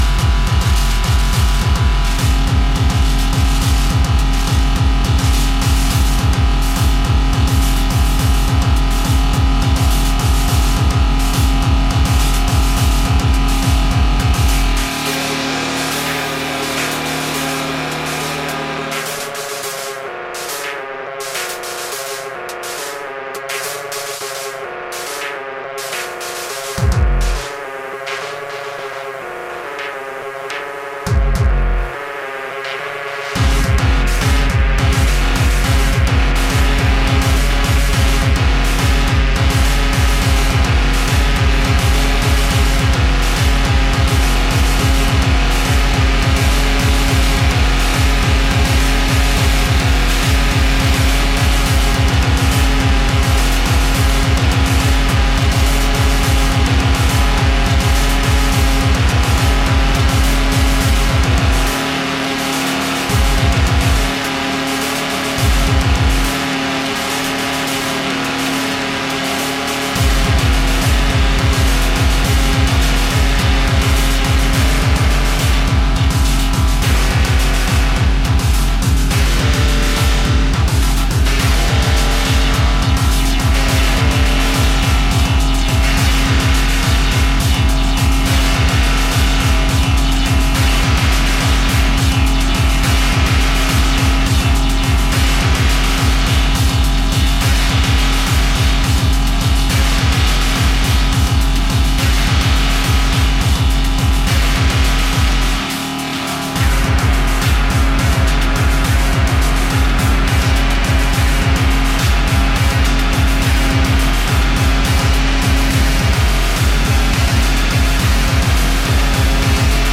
EBM/Industrial, Hardcore, Techno